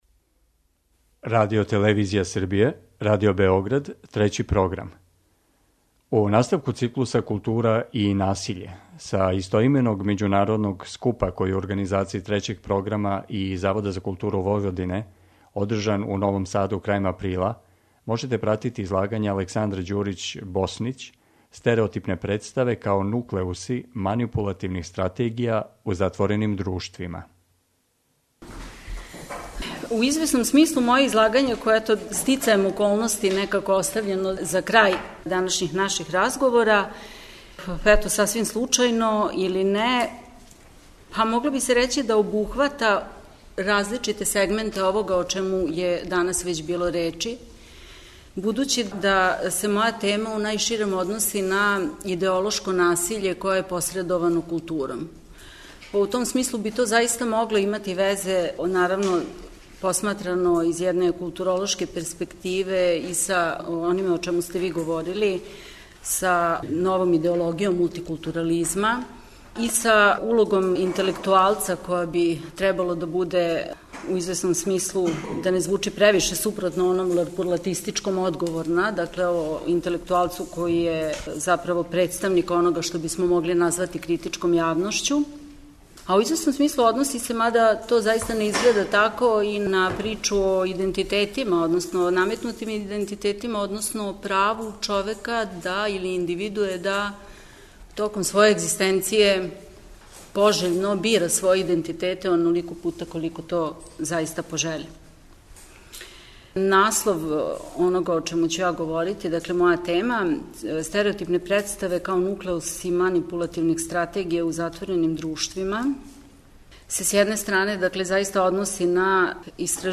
У циклусу КУЛТУРА И НАСИЉЕ, који емитујемо средом, са истоименог научног скупа који су, у Новом Саду крајем априла, организовали Трећи програм и Завод за културу Војводине, емитујемо прилоге са овога скупа и разговоре о излагањима.